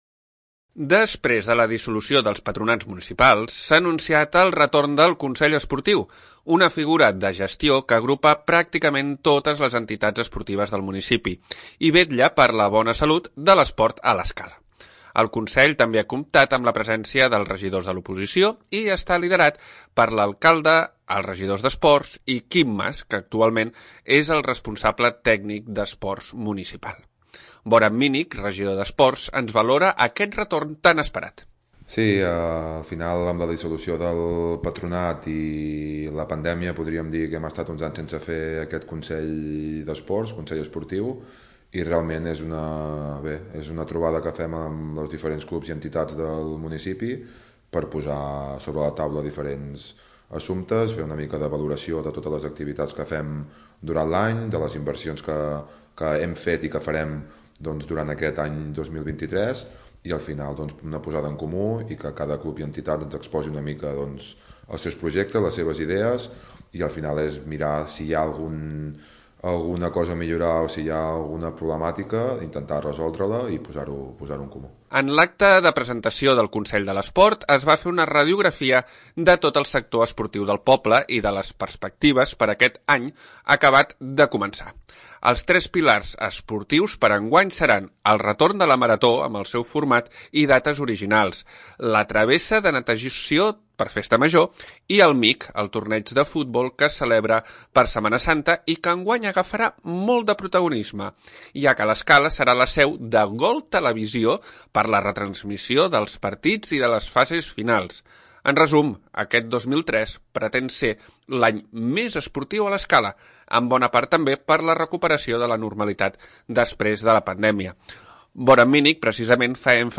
{{ tall TALL 1 BOTXI (RETORN)- Boran Minic, regidor d'Esports ens valora aquest retorn tan esperat }}